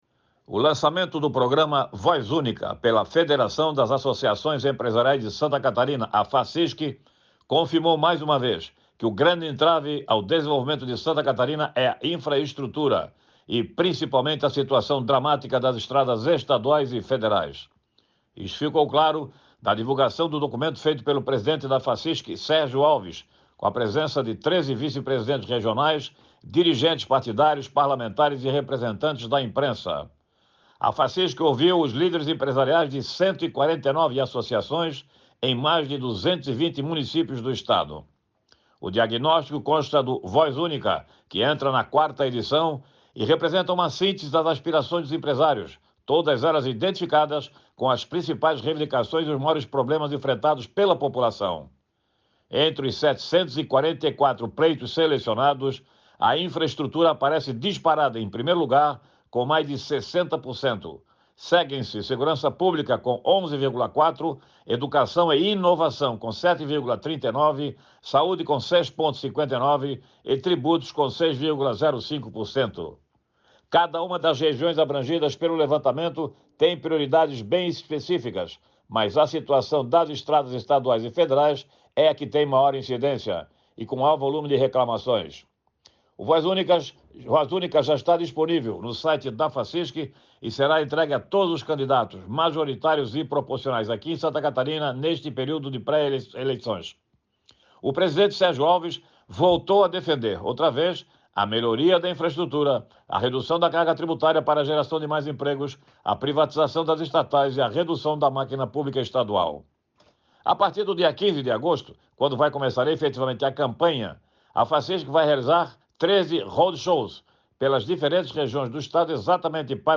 Jornalista destaca comemoração de 191 anos do jornal "O Catharinense" nesta quinta (28) e as diversas atividades pelo Estado